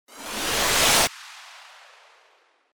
FX-1293-WIPE
FX-1293-WIPE.mp3